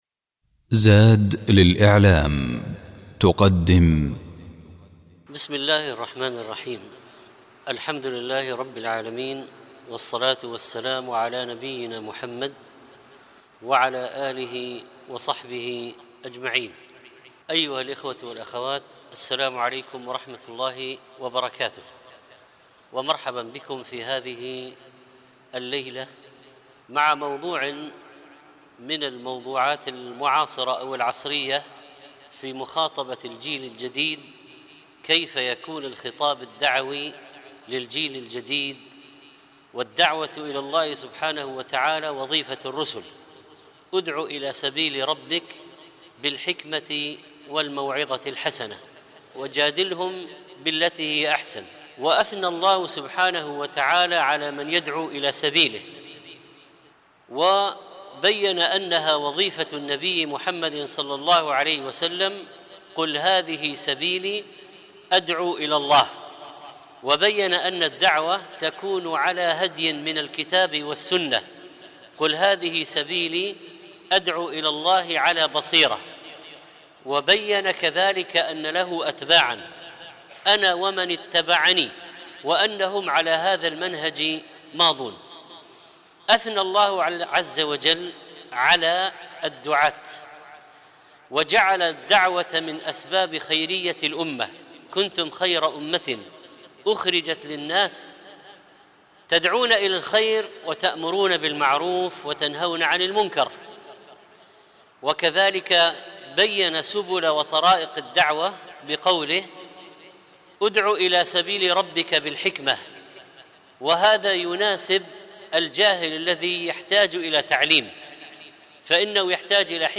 المحاضرات